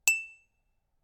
Ting sound from a coffee cup
coffee-cup cup earthen pottery recording stereo sound effect free sound royalty free Sound Effects